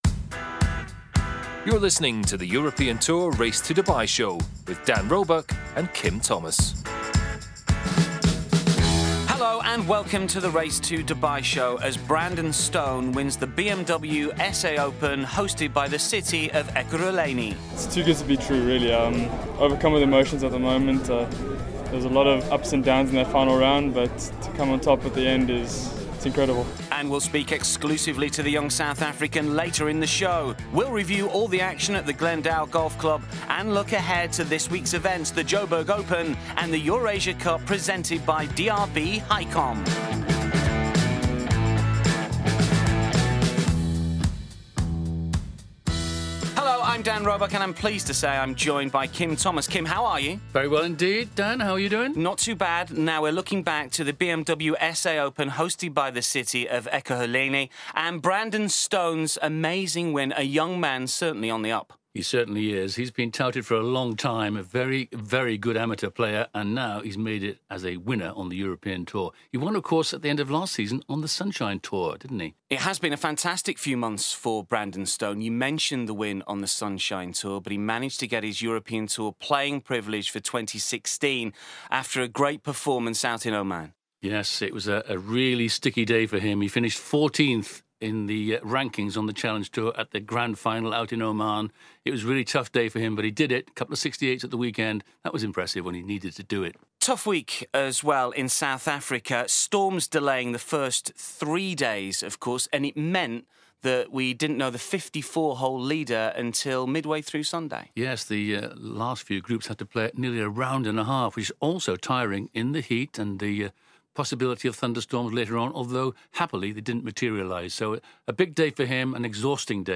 Meanwhile, Shane Lowry speaks ahead of the EURASIA CUP presented by DRB-HICOM and the pair also preview the other European Tour event this week, the Jo’burg Open.